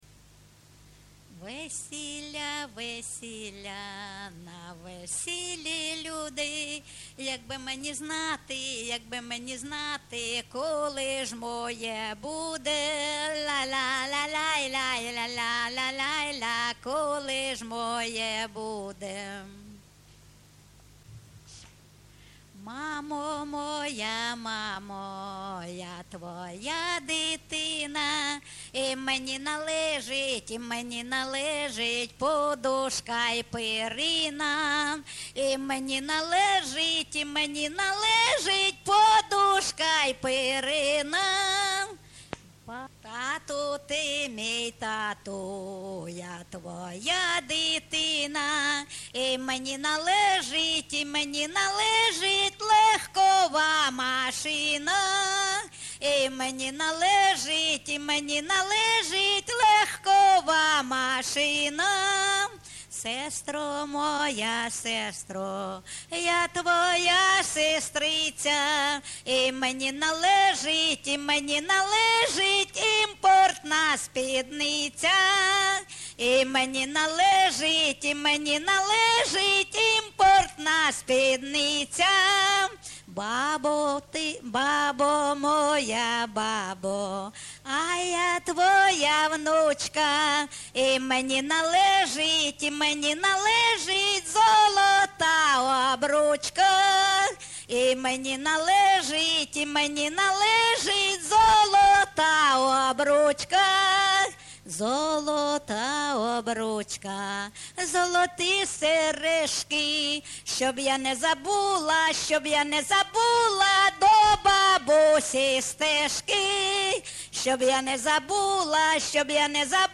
ЖанрСучасні пісні та новотвори
Місце записус-ще Новодонецьке, Краматорський район, Донецька обл., Україна, Слобожанщина